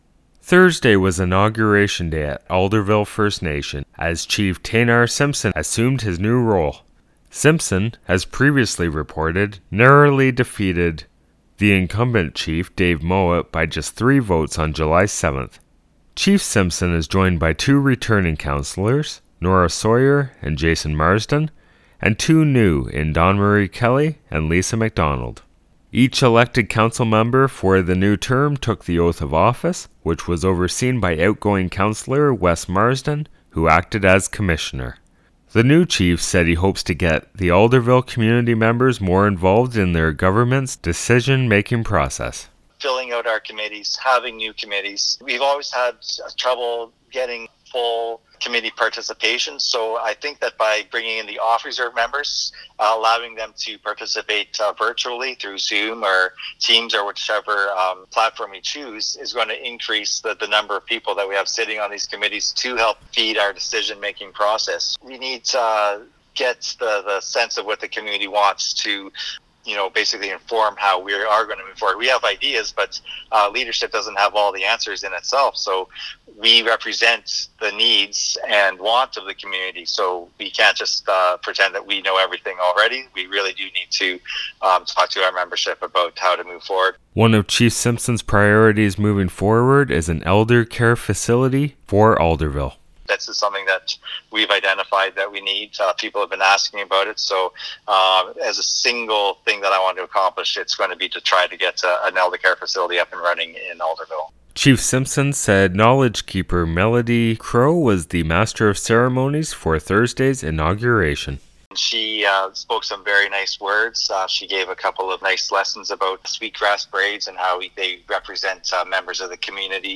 Alderville-inauguration-report-July20.mp3